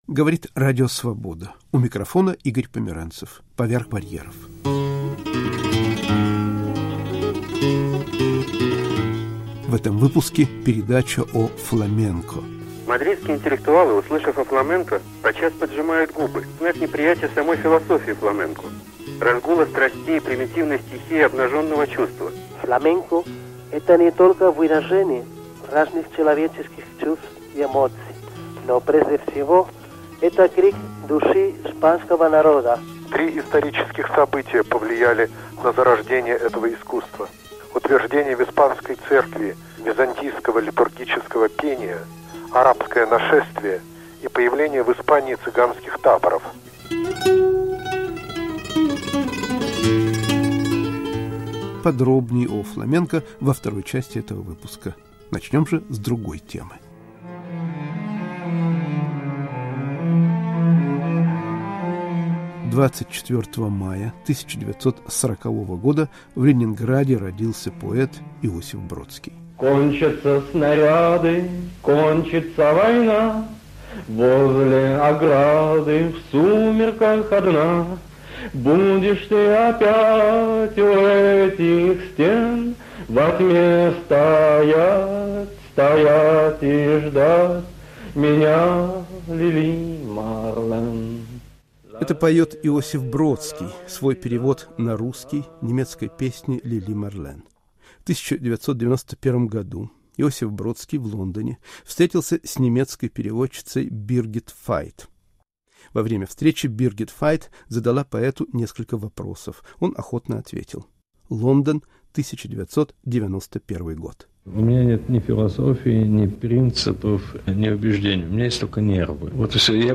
Разговор о ремесле перевода *** Испанские и российские интеллектуалы о Фламенко